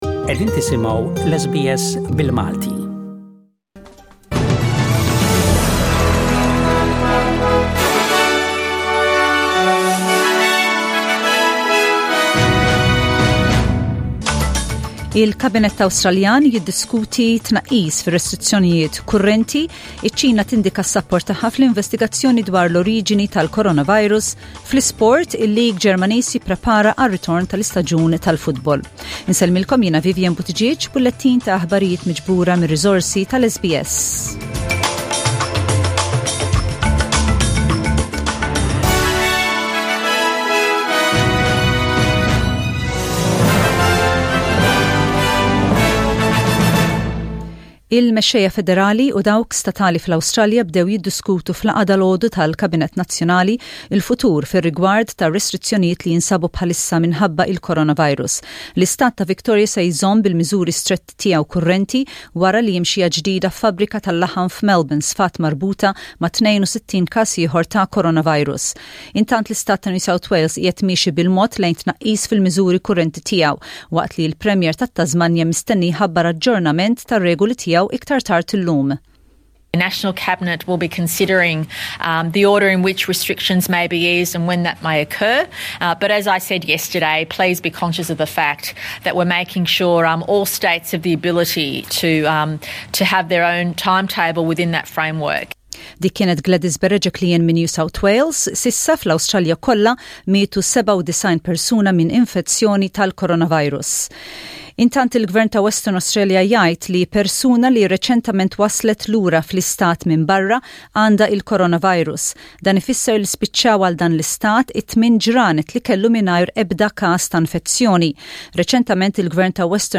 SBS Radio | News in Maltese: 08/05/20